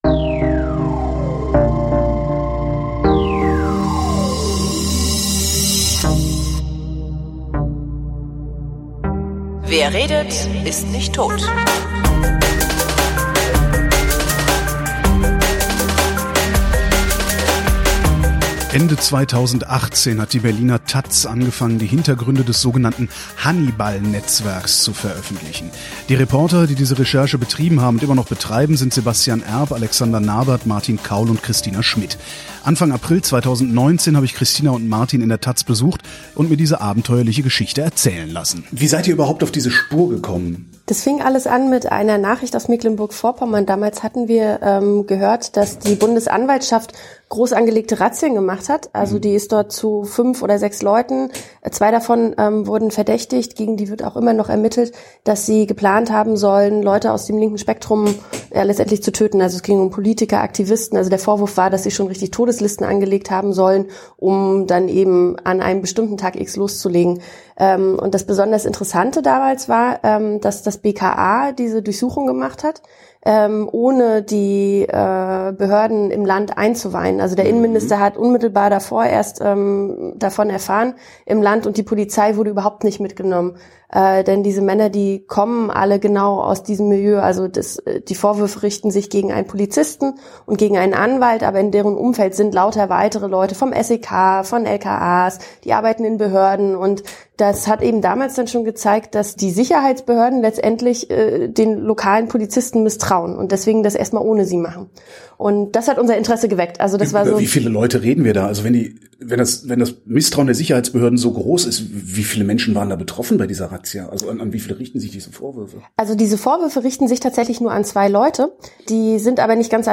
Bitte entschuldigt die schlechte Klagqualität.